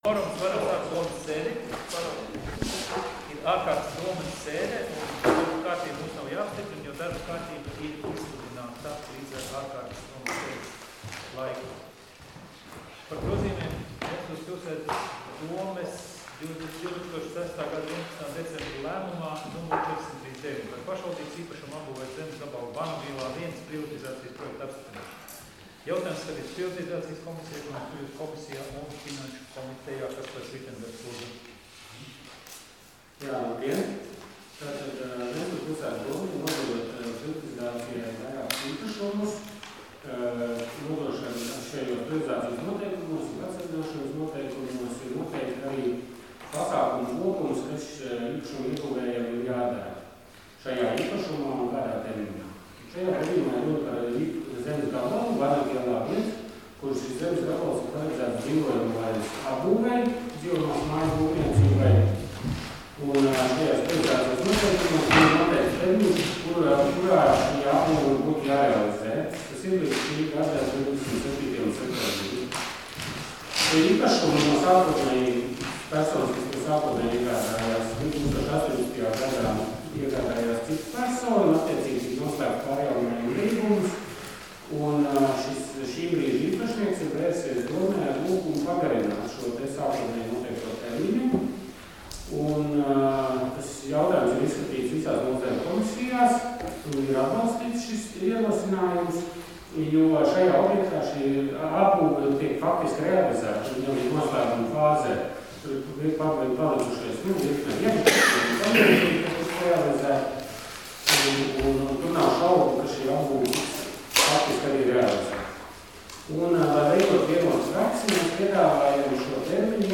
Domes ārkārtas sēdes 18.12.2020. audioieraksts